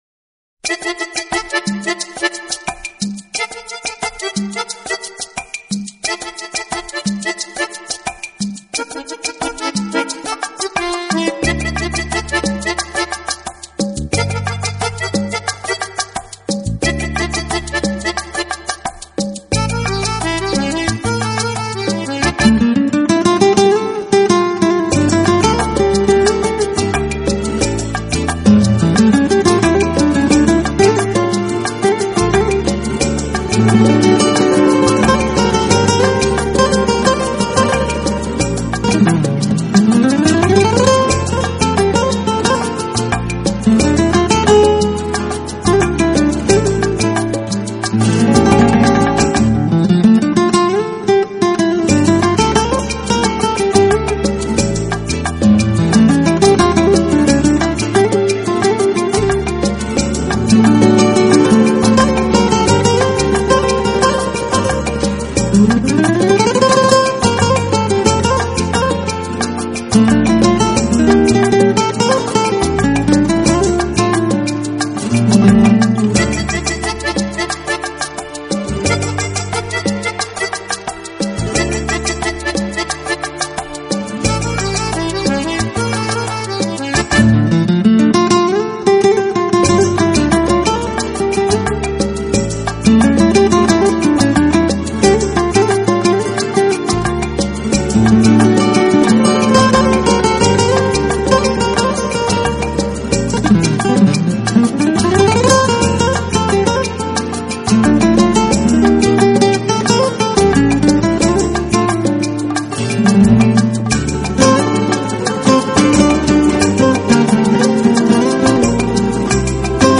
专辑语言：纯音乐